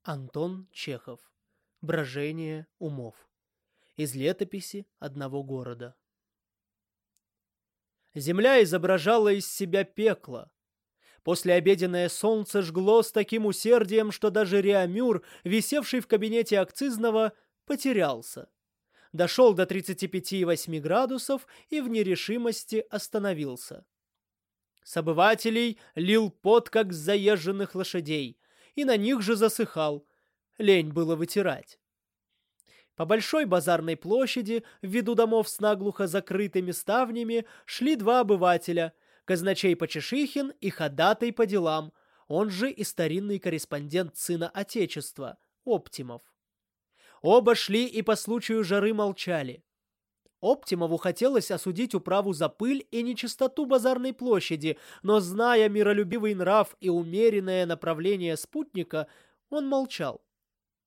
Аудиокнига Брожение умов | Библиотека аудиокниг